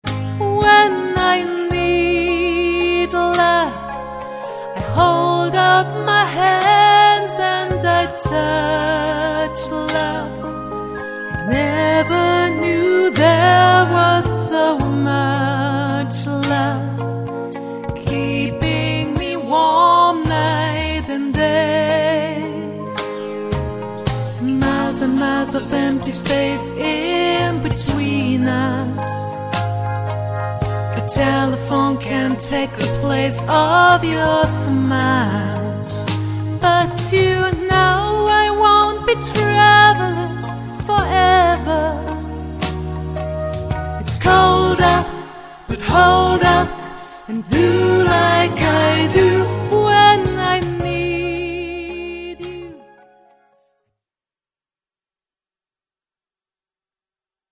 aus den Sparten Pop, Musical und Deutsch-Chanson